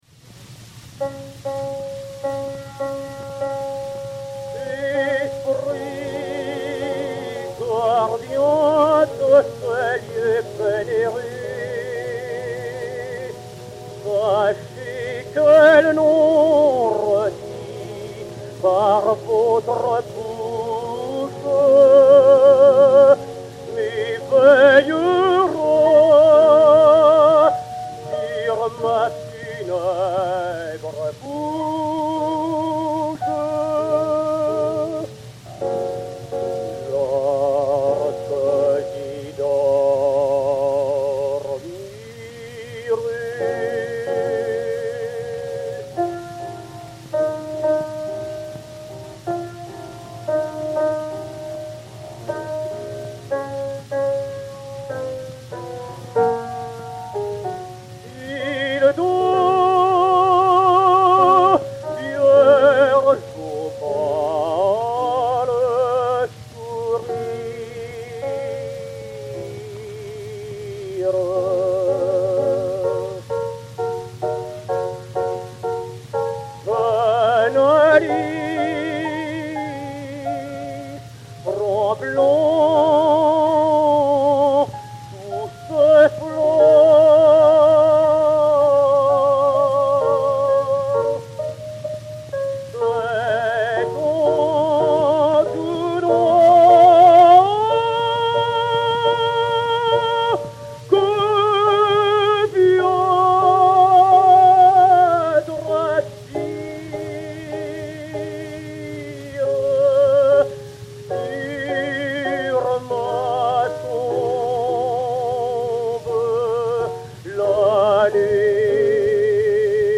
ténor de l'Opéra Piano